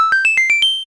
Edition media files Category:Sound effect media files You cannot overwrite this file.
1-Up (sound effect)